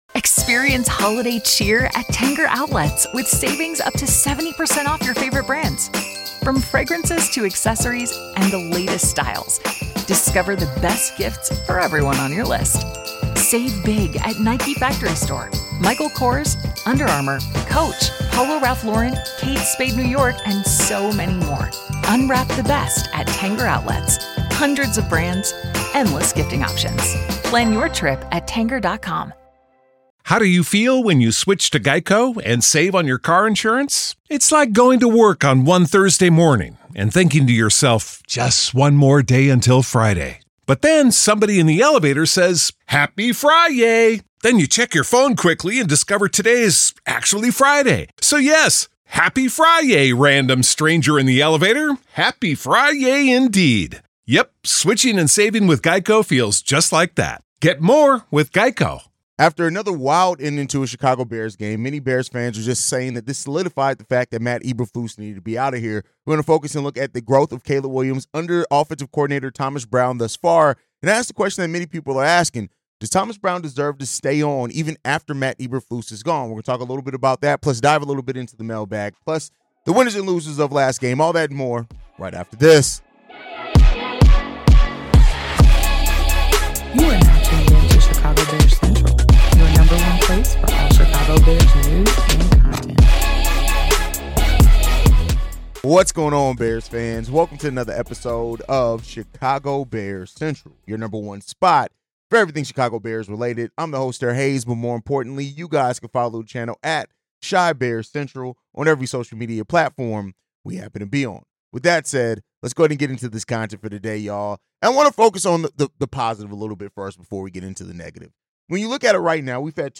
The episode also features listener voicemails, offering passionate fan perspectives on the team's current state and future prospects. As the Bears face a critical Thanksgiving matchup, the pressure mounts for decisive action to turn the season around.